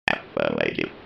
دانلود صدای ربات 36 از ساعد نیوز با لینک مستقیم و کیفیت بالا
جلوه های صوتی